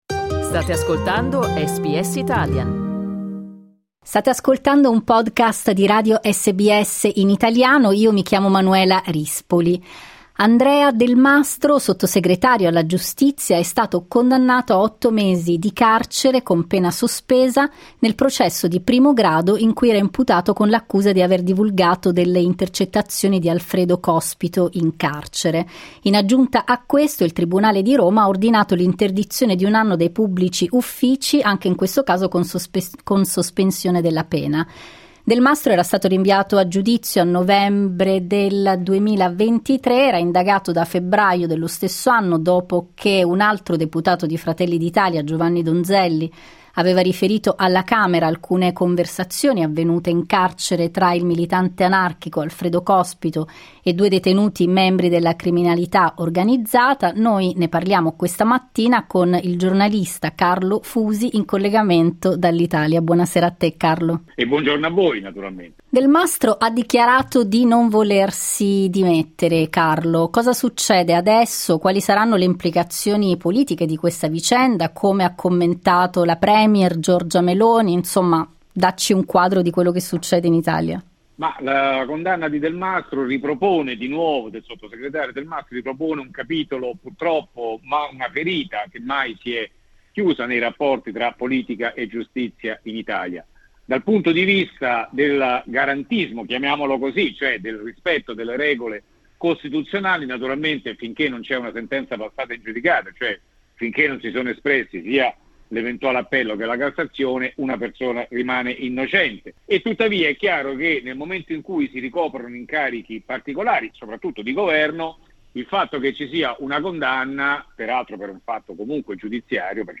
SBS in Italiano
per ascoltare l'analisi del giornalista